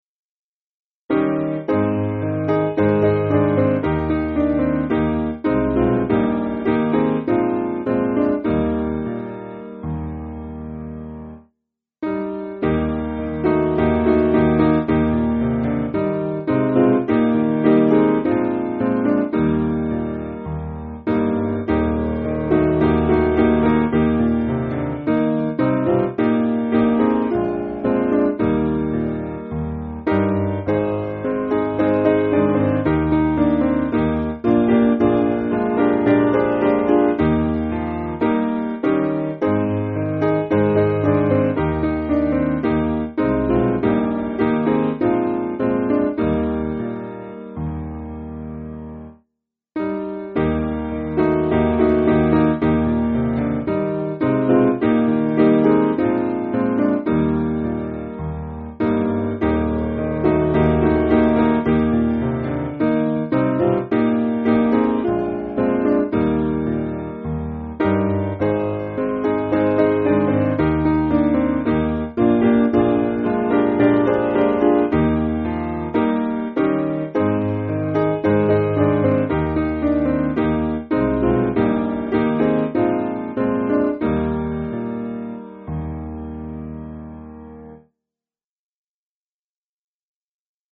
Simple Piano
(CM)   2/Eb